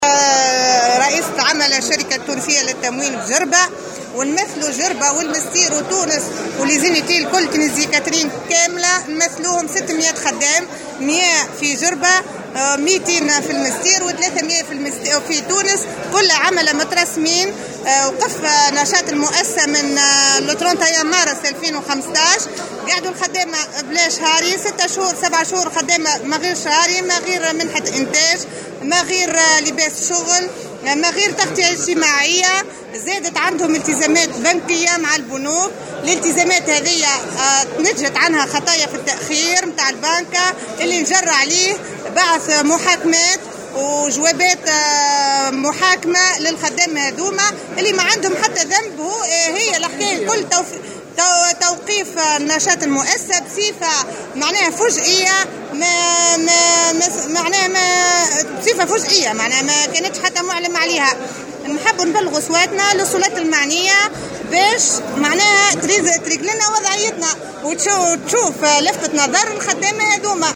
إحدى المحتجات